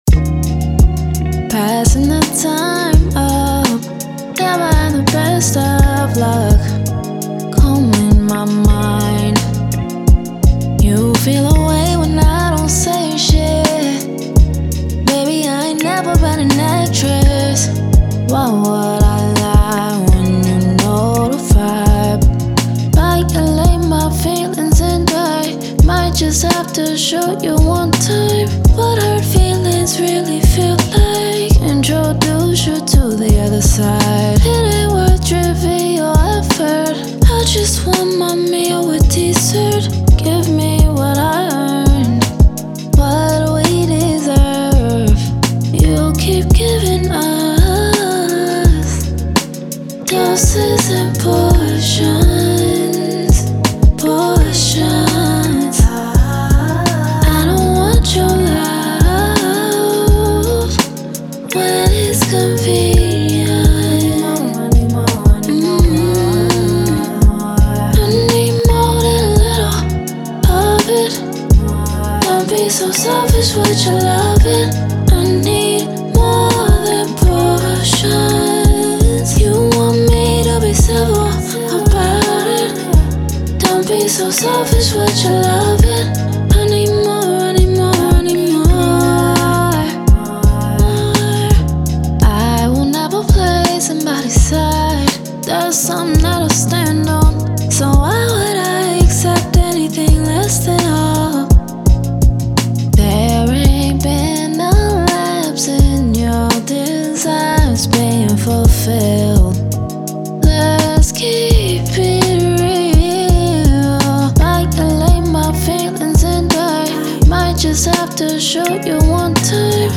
R&B, Soul
Eb Minor